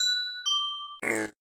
pigclock.ogg